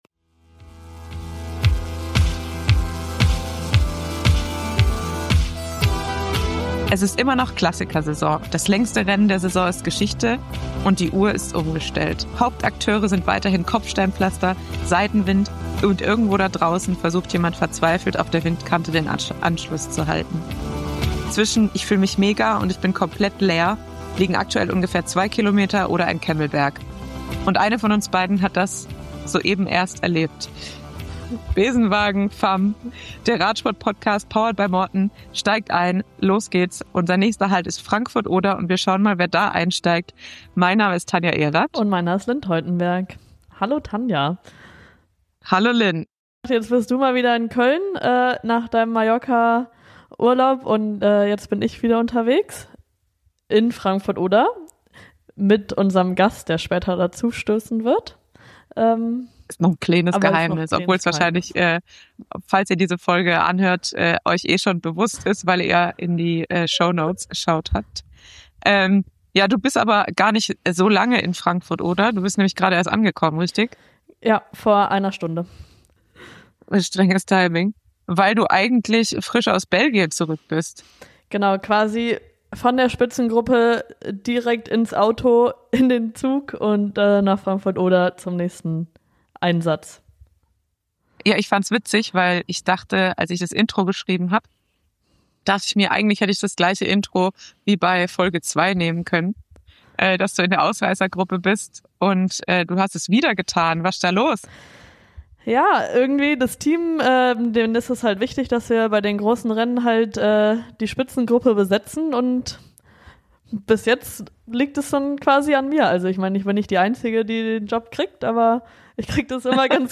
Das neue Host-Duo sammelt euch und Profis auf, um über das Leben auf und neben dem Rad zu plaudern.